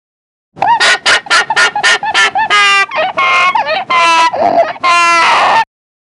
Alpaca Alarm Call
Alpaca-Alarm-Call.mp3